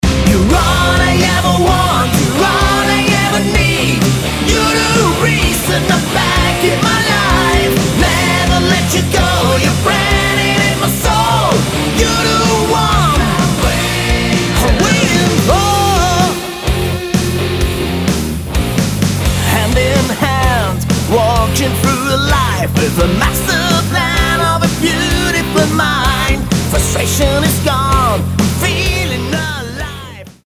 Lead Vocals
Guitar
Drums